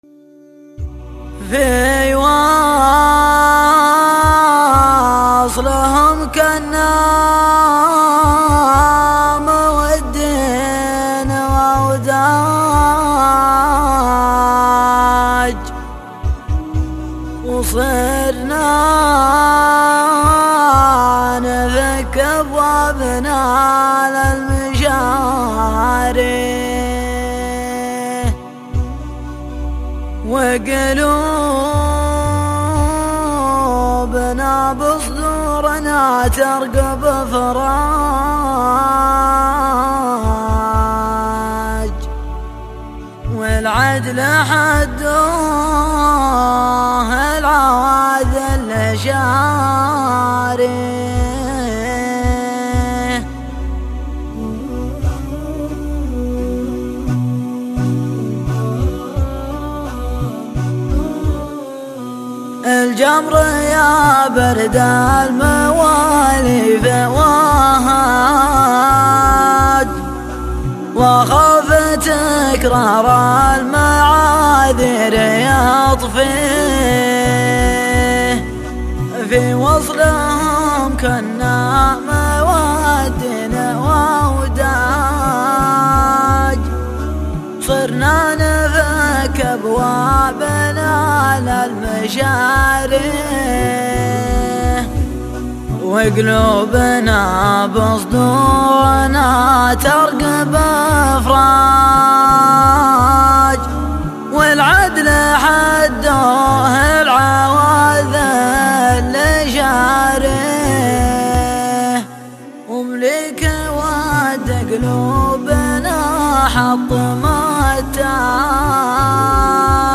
:: الشيلات